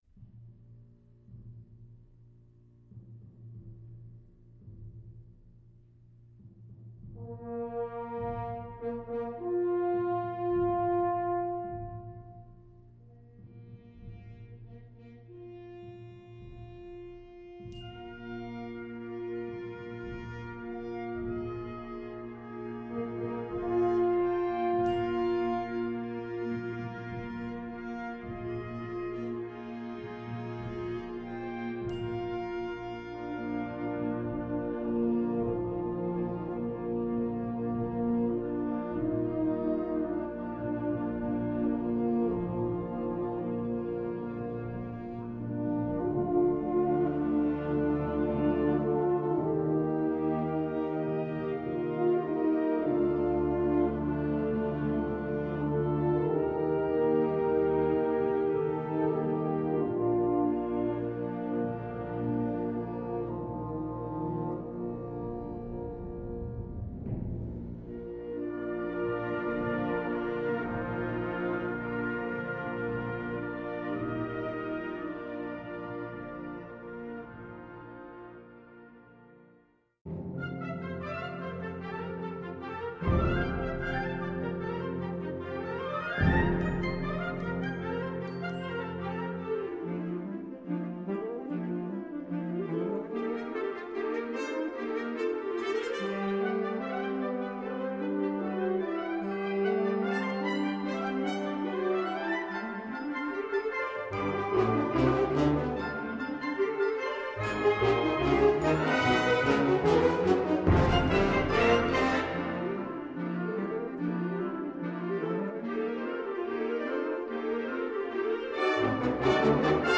Gattung: Konzertstück
Besetzung: Blasorchester